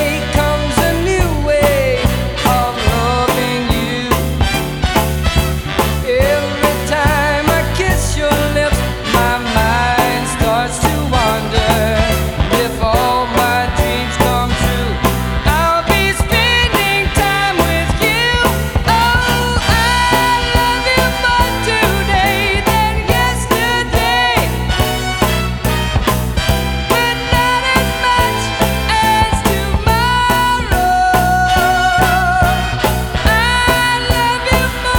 Жанр: Поп музыка / Рок
Psychedelic, Adult Contemporary